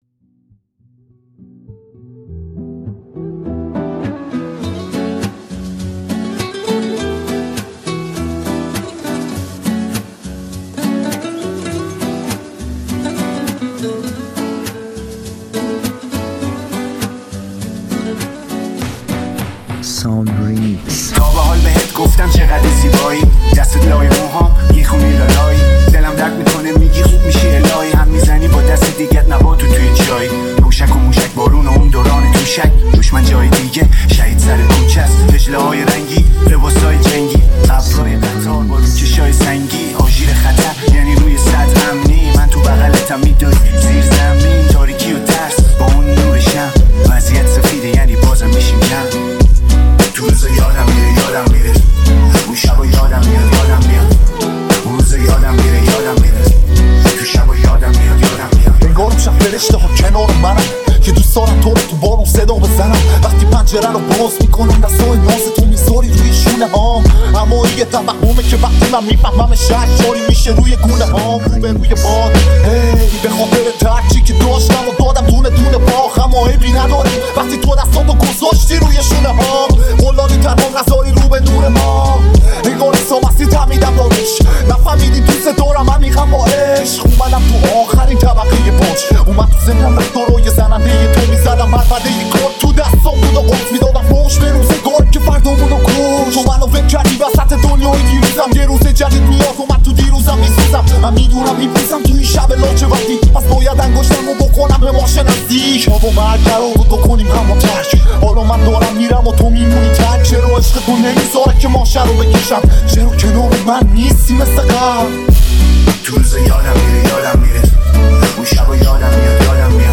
remix khobi bod